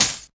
70 HAT 3.wav